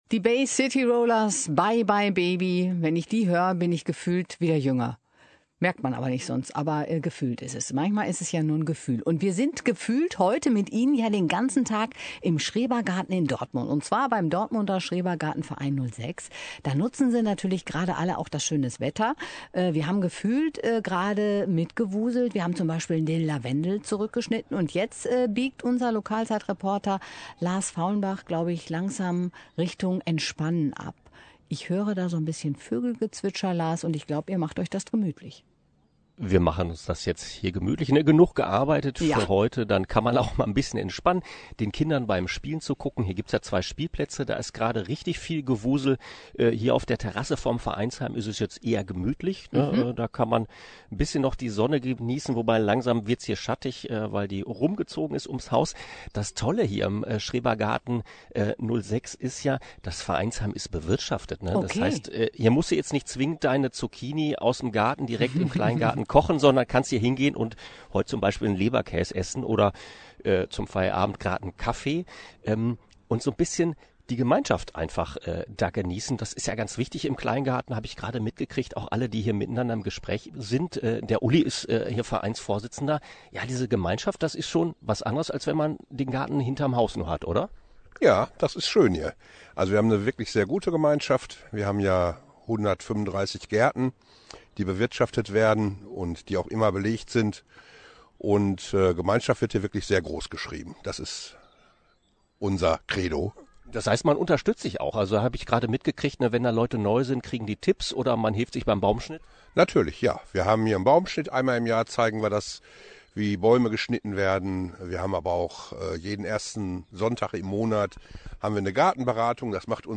Zum Abschluss gab es gegen 17:15 die letzte Liveschalte von der Terrasse am Vereinshaus zum Thema "Gemeinschaft".